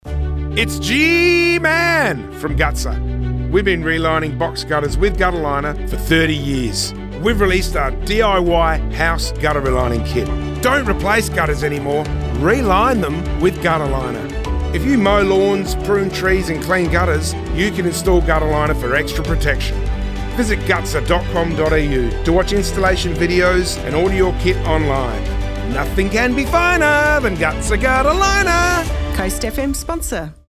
2025 Radio Add